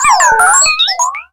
Cri de Spododo dans Pokémon Soleil et Lune.